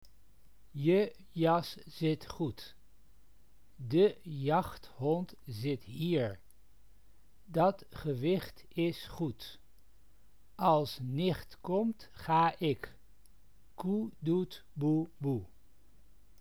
This page describes the rules of pronunciation, as used by the Amsterdam Portuguese community.
It shows how to pronounce the letters and the vowels for Dutch speakers.